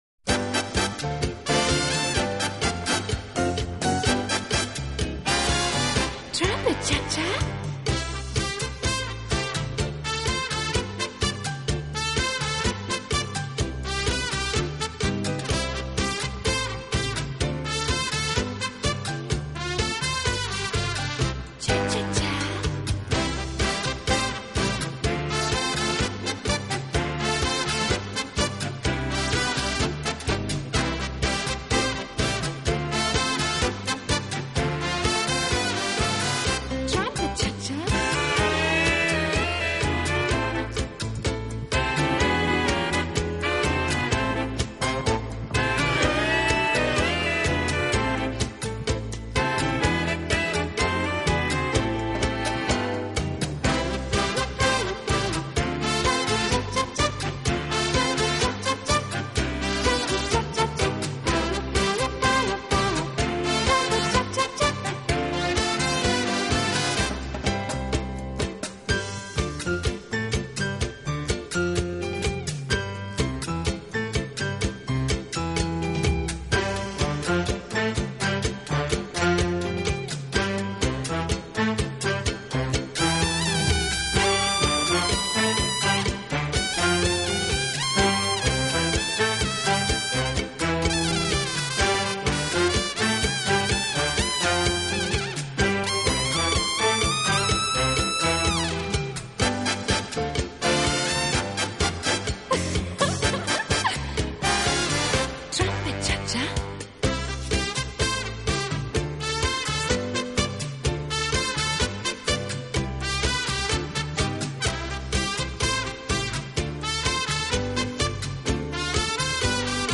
【轻音乐】
舞曲著名，曾多次在国际标准舞世界锦标赛担任音乐和节奏定标。
Cha-Cha 32 T/M